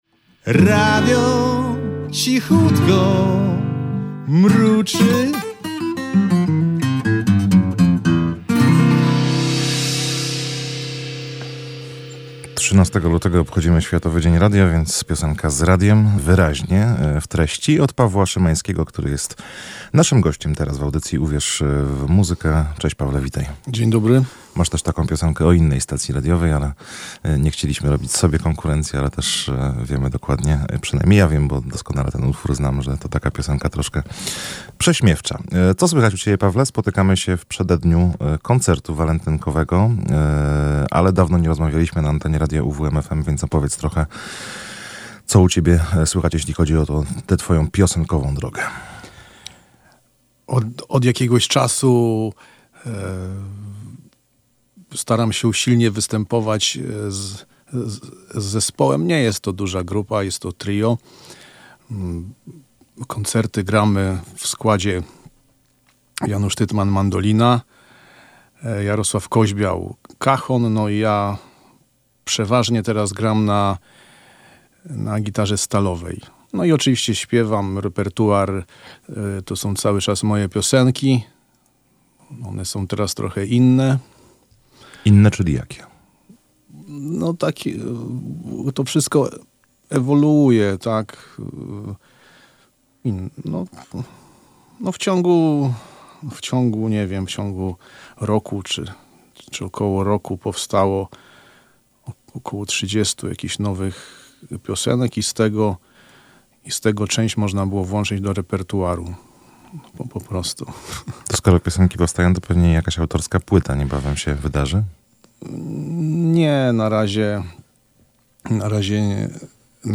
rozmawiał